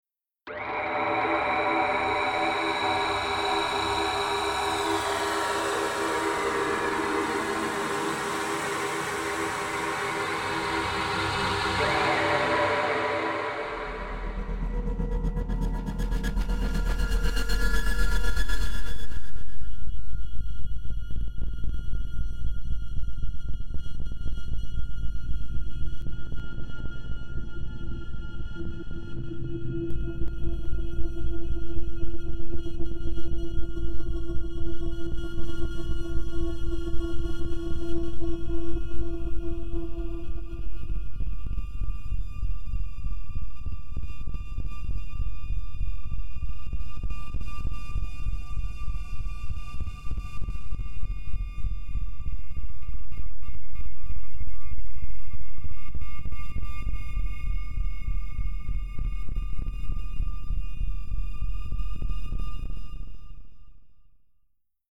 Captés avec le plus grand soin sur enregistreur Sound-Device et microphone Neuman double MS km 120-140, mastering protools 24b-48kHz ou 24b-96kHz.
sfx